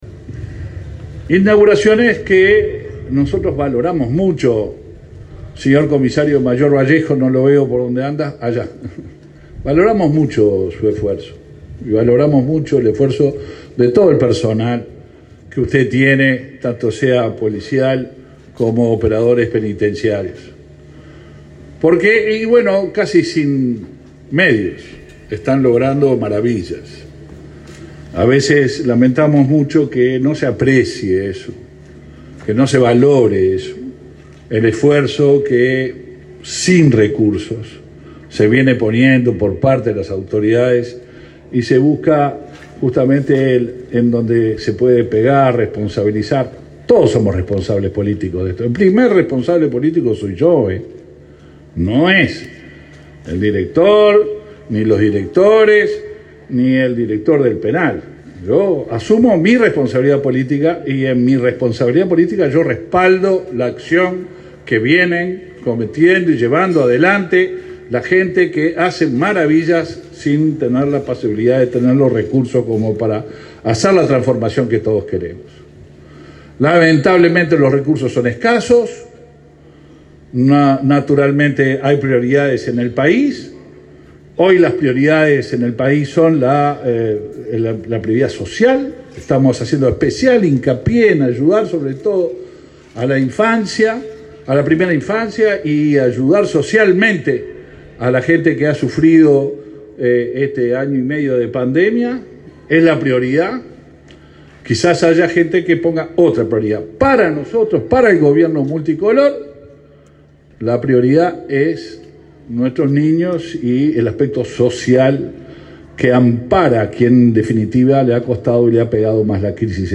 Palabras del ministro del Interior, Luis Alberto Heber
El ministro del Interior, Luis Alberto Heber, este martes 31 visitó el centro penitenciario Las Rosas, en Maldonado, donde inauguró módulos, un aula